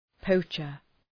{‘pəʋtʃər}